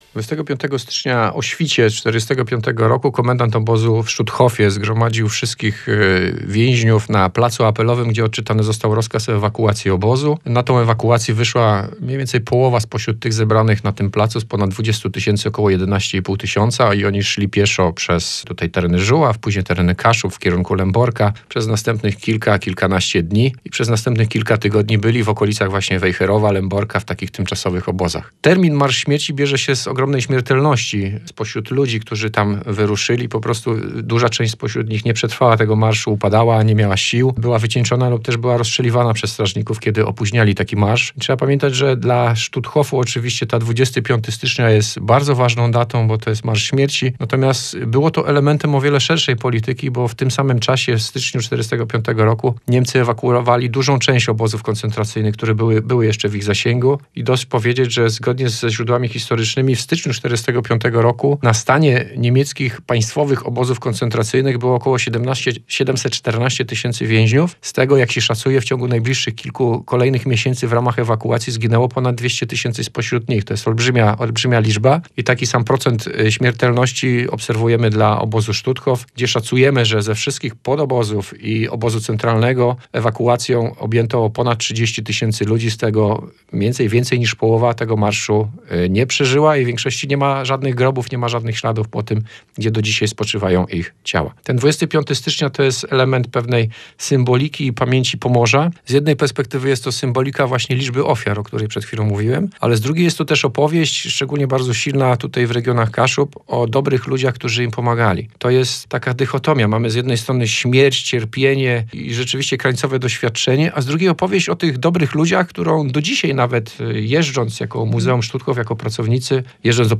na antenie Radia Gdańsk